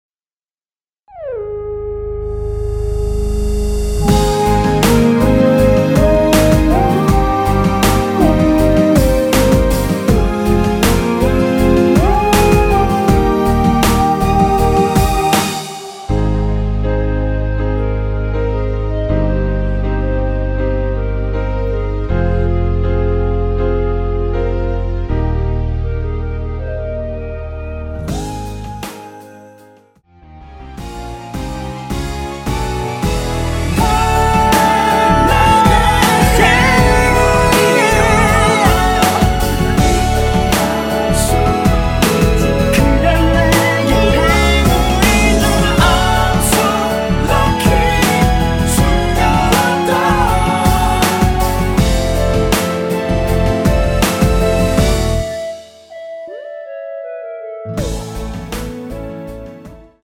원키에서(-1)내린 멜로디와 코러스 포함된 MR입니다.(미리듣기 확인)
Ab
앞부분30초, 뒷부분30초씩 편집해서 올려 드리고 있습니다.
중간에 음이 끈어지고 다시 나오는 이유는